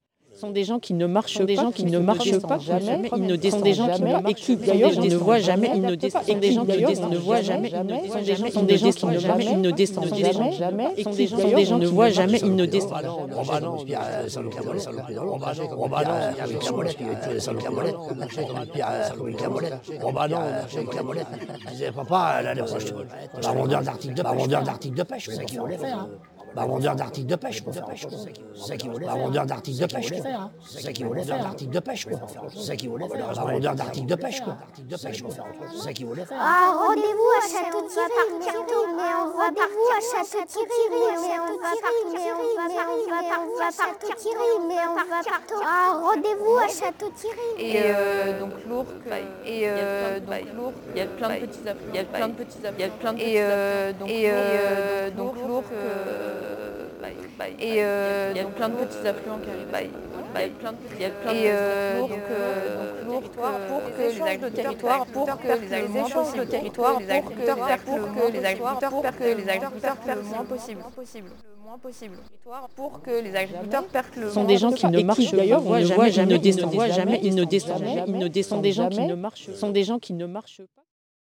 Avec les patches PureData ExMM93-PlayGrain2-3